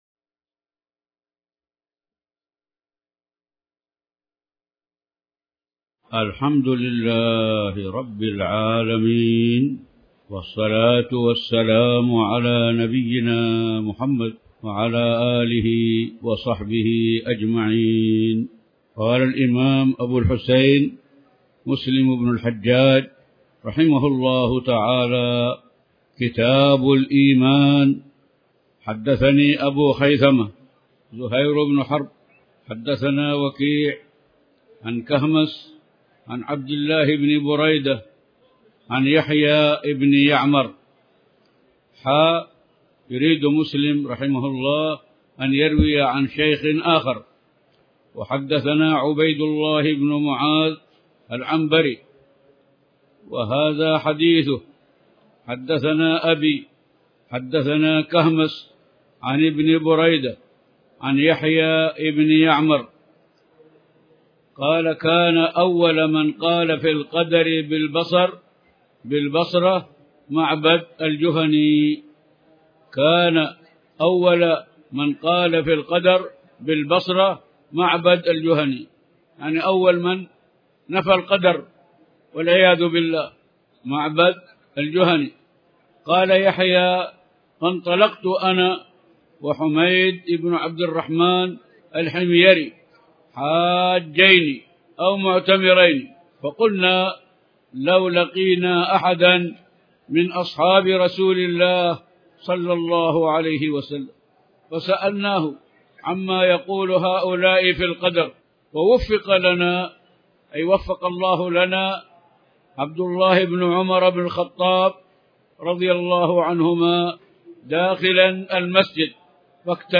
تاريخ النشر ٢٣ ذو الحجة ١٤٣٩ هـ المكان: المسجد الحرام الشيخ